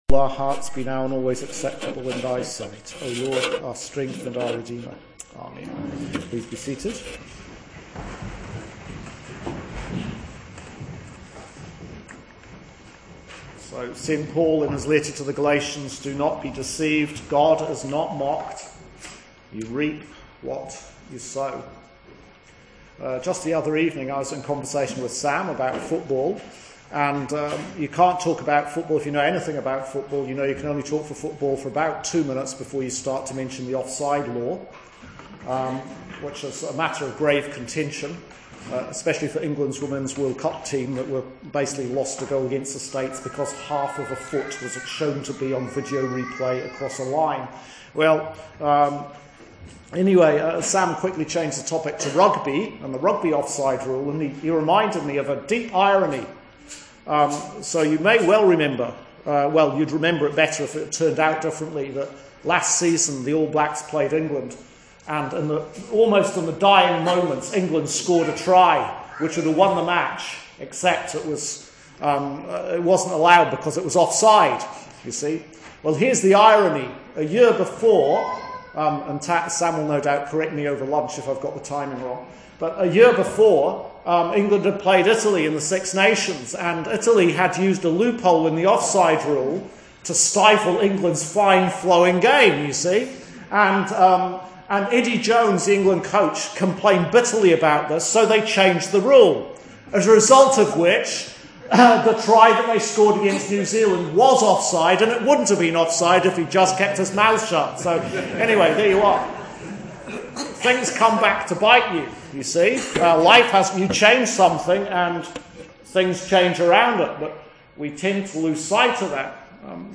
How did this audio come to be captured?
Evensong Genesis 41 Luke 16:1-9